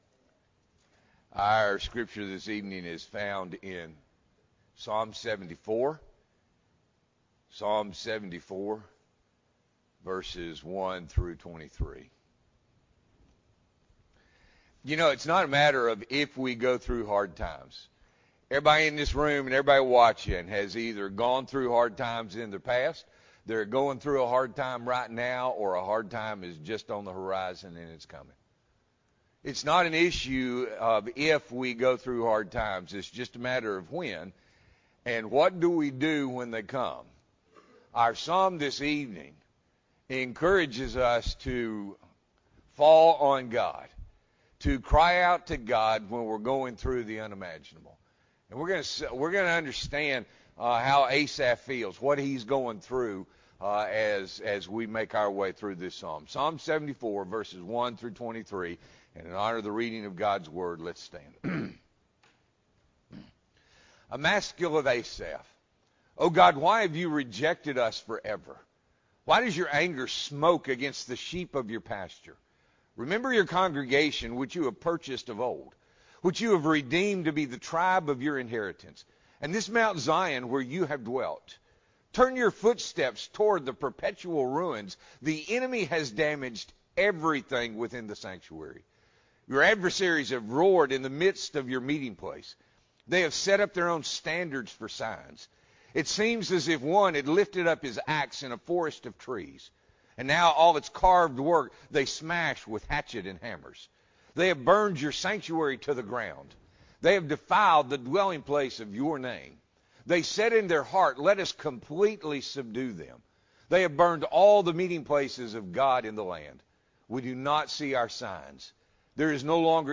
May 5, 2024 – Evening Worship